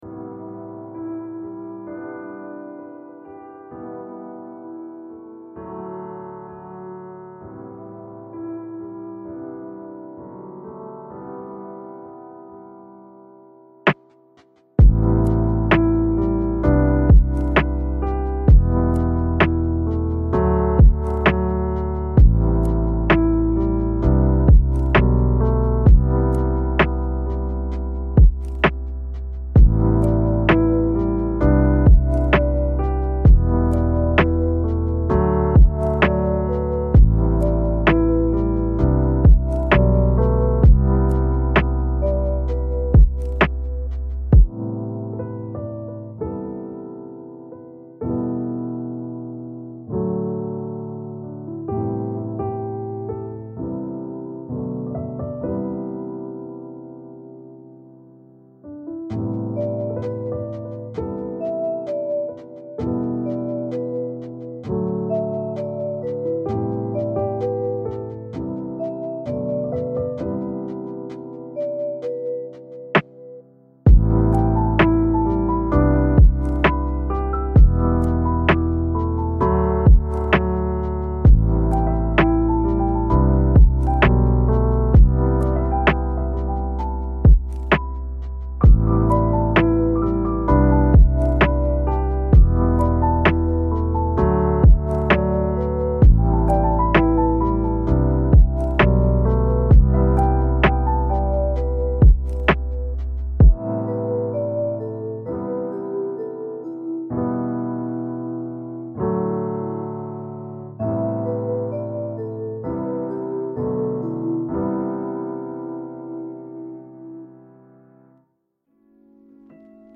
Chopin : Piano Étude Profonde